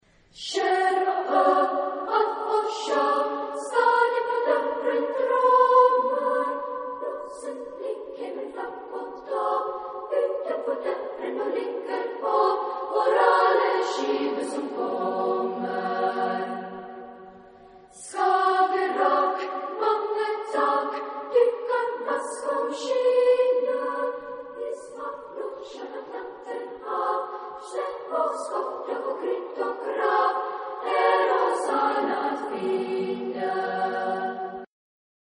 Genre-Stil-Form: weltlich ; Lied
Chorgattung: SSA  (3 Kinderchor ODER Frauenchor Stimmen )
Tonart(en): G-Dur